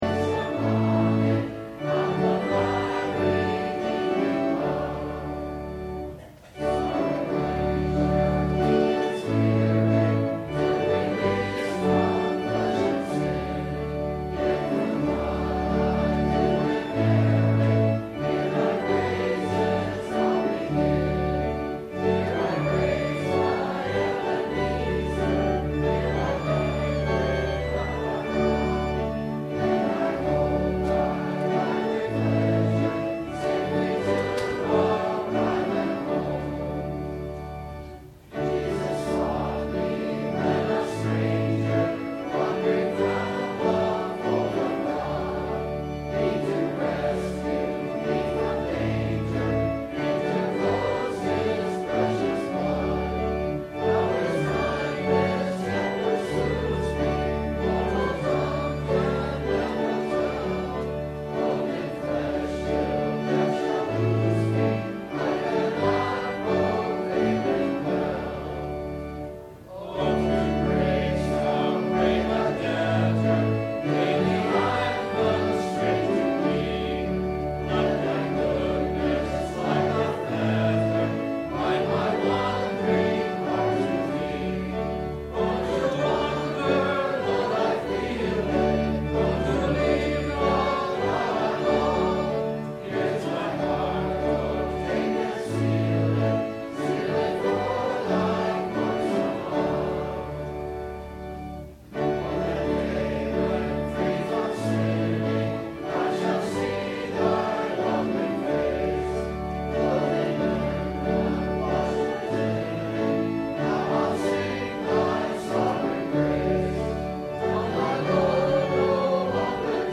Is God a Liberal - Sermon - November 09 2008 - Christ Lutheran Cape Canaveral